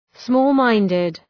Προφορά
{‘smɔ:l,maındıd}